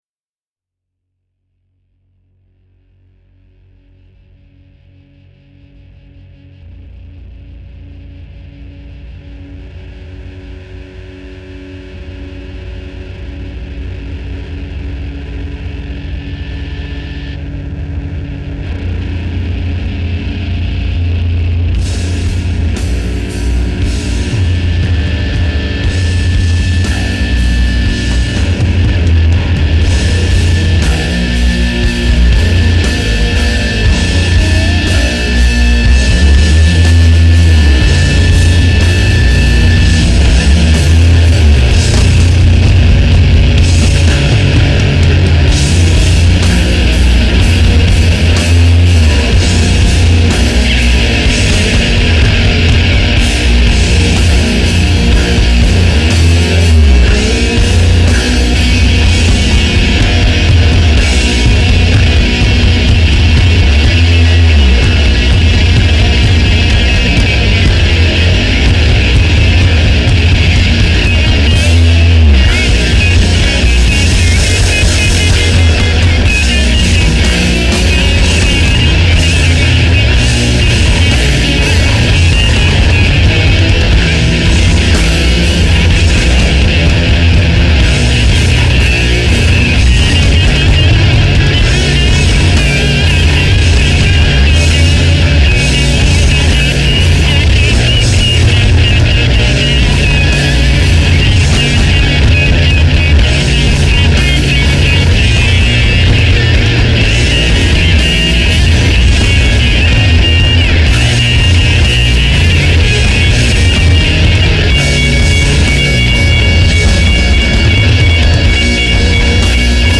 stoner doom metal
Doom metal
Stoner metal